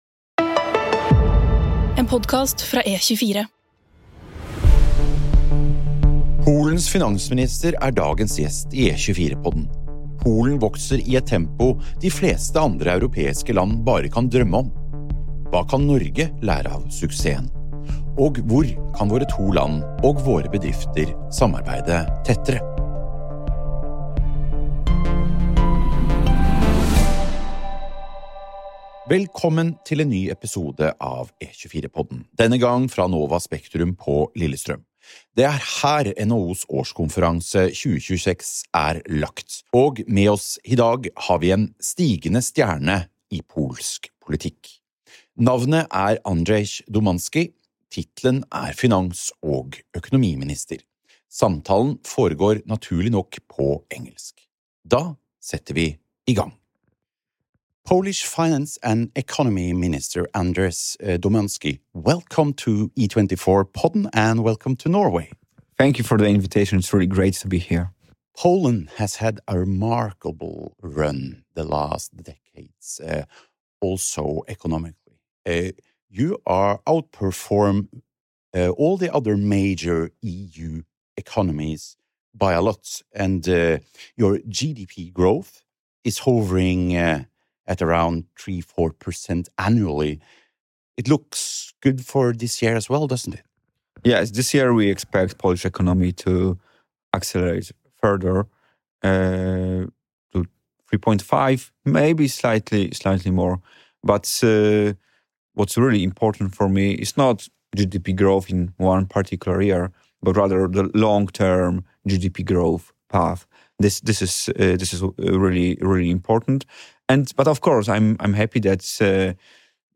Polens finansminister er dagens gjest i E24-podden.
Med Andrzej Domański, Polens finans-og økonomiminister.